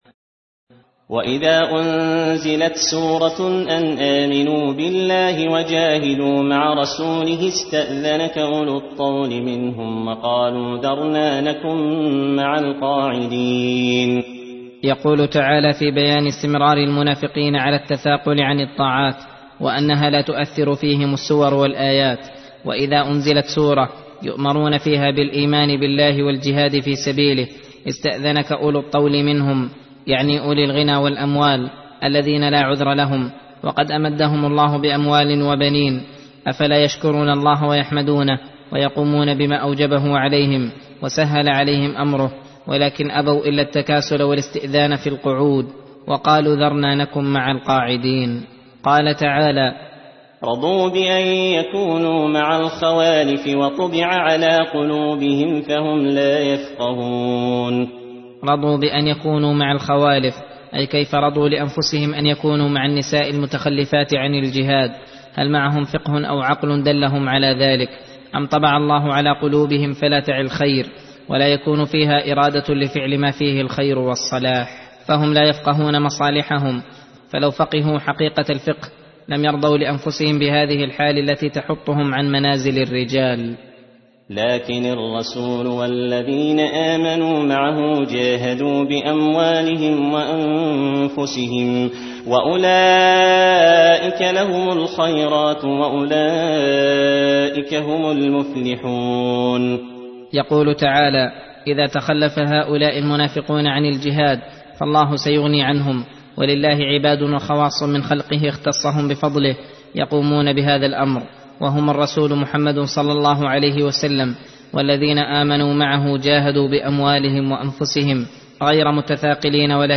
درس (22) : تفسير سورة التوبة (86-103)